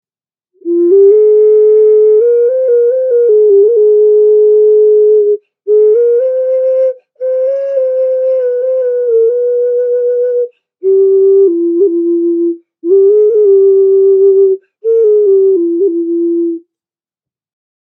Reptile Ocarina Sound Healing Instruments Ceramic Flutes
This Instrument produces a lovely melody and range.
A recording of the sound of this particular ocarina is in the top description, just click on the play icon to hear the sound.
First I collect the clay and hand coil it into the shape of the Reptile Ocarina This instrument can be Sound Healing and has a melodic sound.